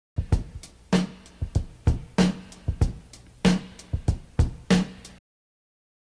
Loops, breaks